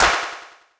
cch_04_clap_one_shot_mid_delay_why.wav